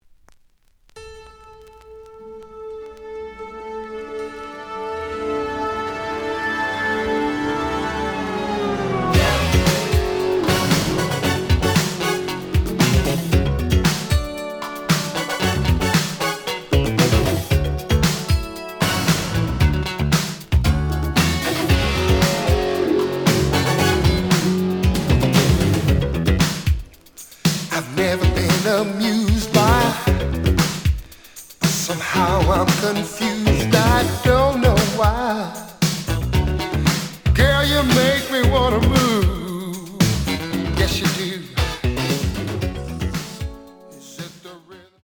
試聴は実際のレコードから録音しています。
●Genre: Soul, 80's / 90's Soul
●Record Grading: VG~VG+ (A面のラベルに書き込み。盤に若干の歪み。プレイOK。)